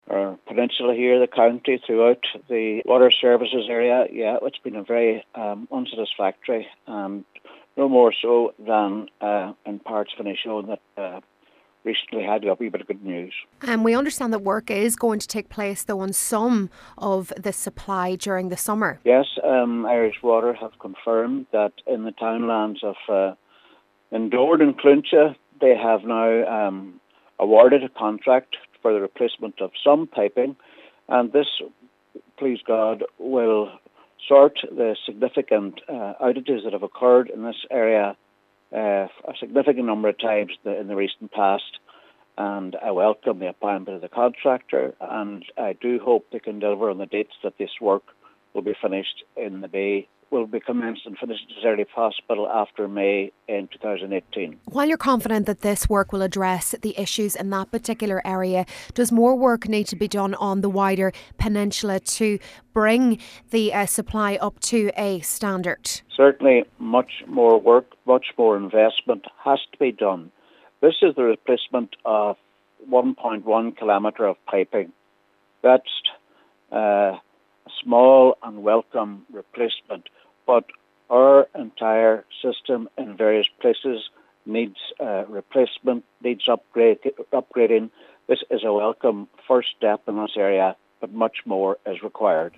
Cllr. Albert Doherty while this is a step in the right direction, much more needs to be done to address the wider issues on the peninsula: